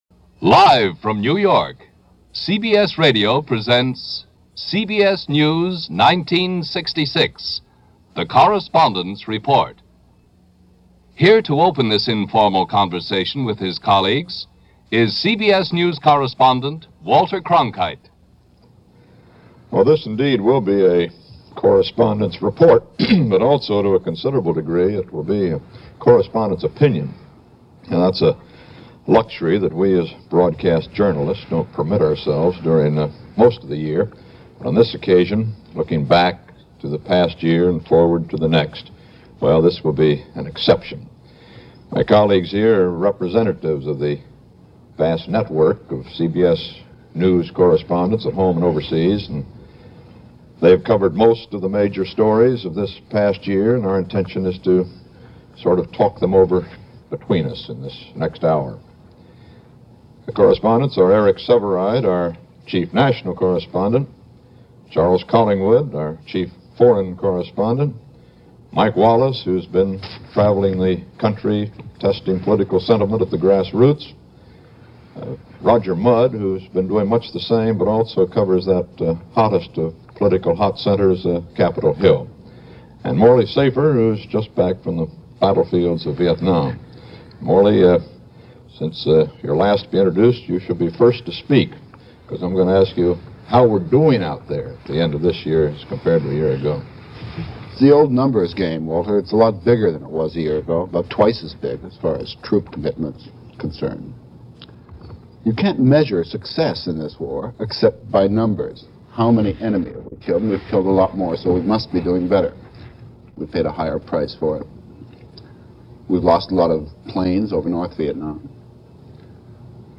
In the featured audio presentation (below), CBS News presents the network’s correspondents year-end views, opinions, and share their perspective on Viet Nam, and other news of 1966.
CBS-Radio-Network-Walter-Cronkite-Correspondents-Report-Vietnam-and-News-1966-USARM-Audio-Remastered.mp3